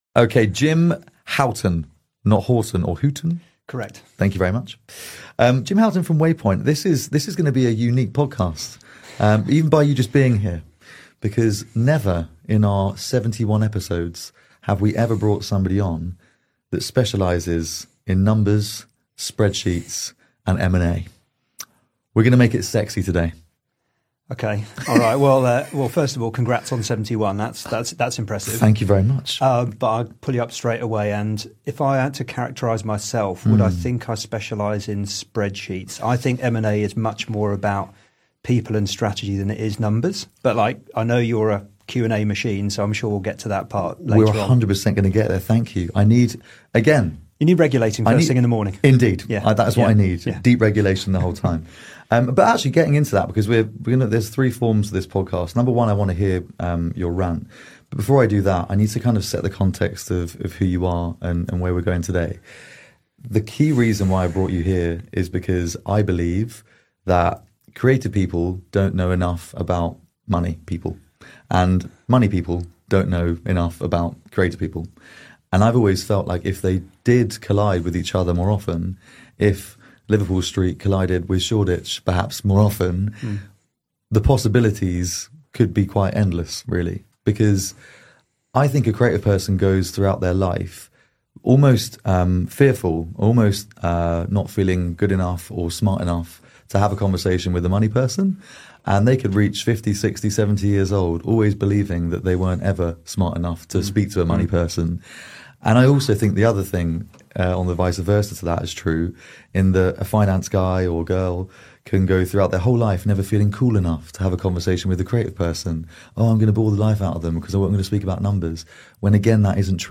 But this isn’t a finance podcast. It’s the conversation creative people never get, but absolutely need.